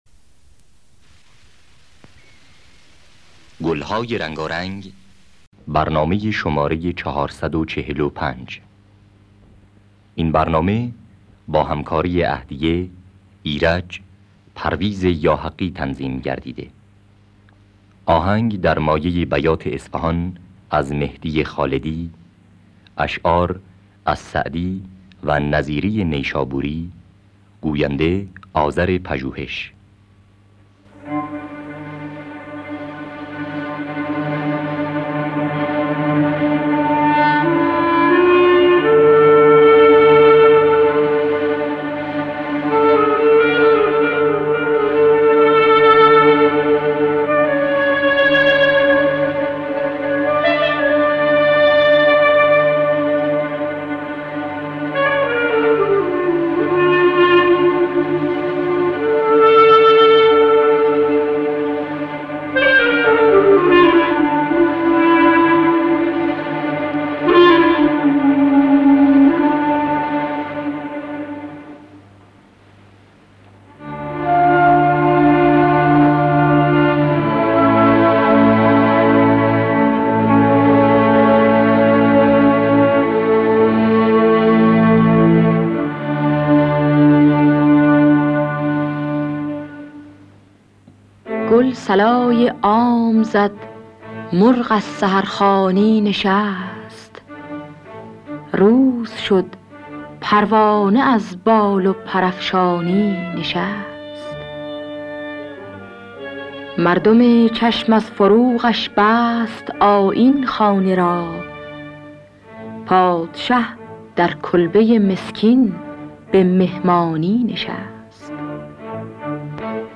گلهای رنگارنگ ۴۴۵ - بیات اصفهان
خوانندگان: عهدیه ایرج نوازندگان: پرویز یاحقی
گوینده: آذر پژوهش